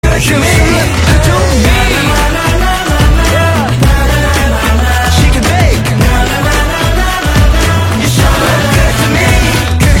LYRE_100_vocal_gang_lalala_powerful_anthem_wet_Emin